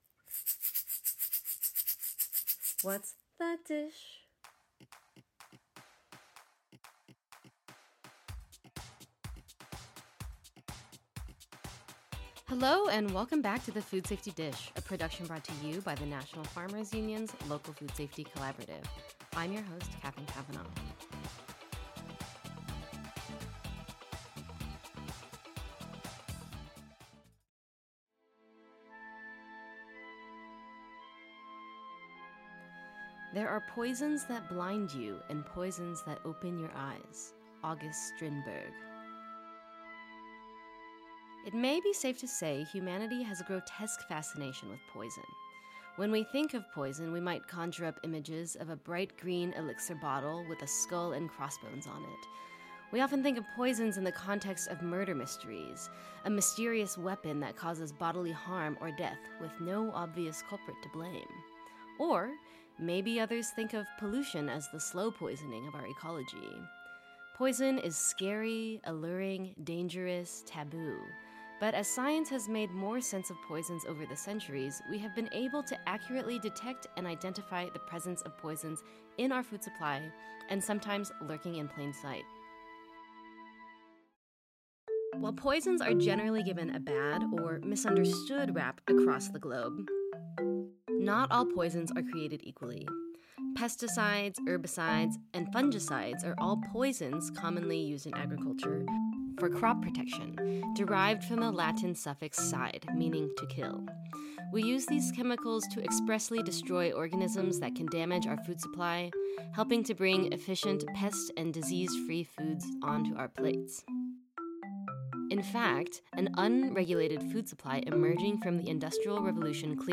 The Food Safety Dish is a production dishing on all things food safety, brought to you by National Farmers Union's Local Food Safety Collaborative. We talk with farmer and expert voices on their subject specialties: sharing practical advice, wisdom, and how to incorporate good food safety practices into your own growing operation.